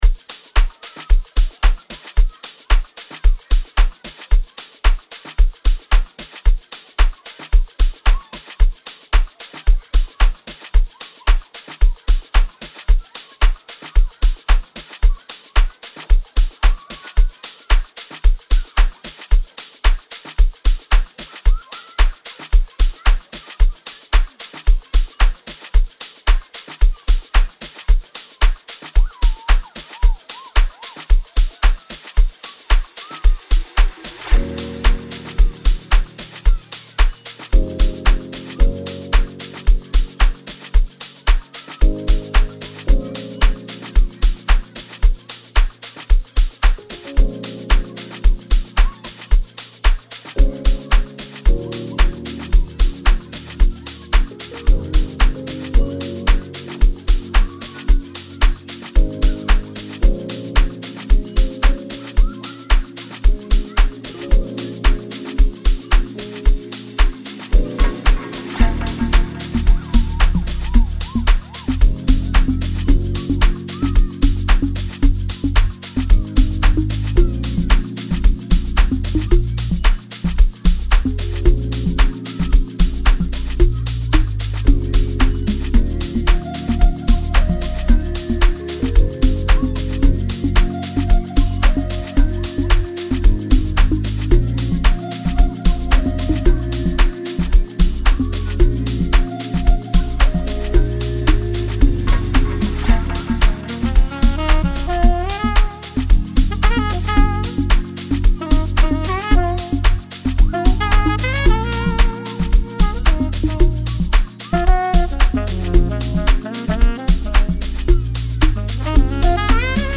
a genre of house music that originated in South Africa.